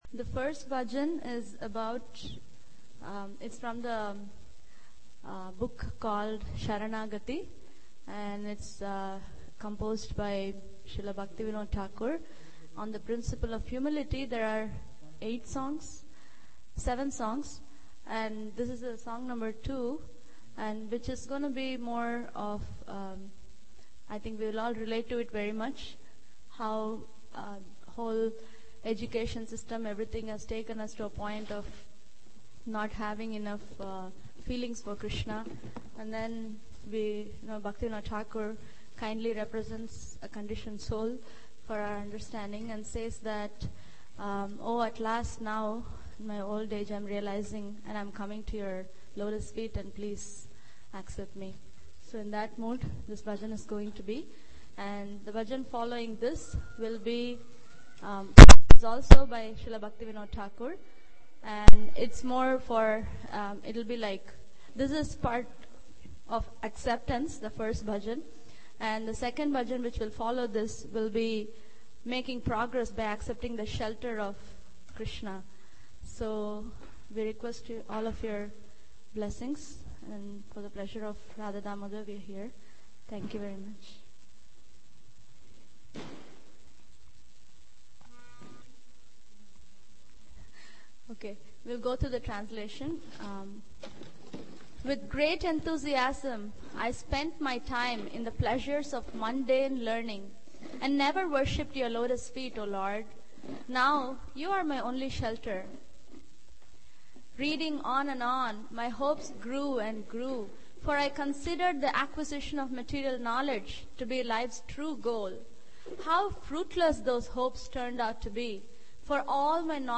Bhajans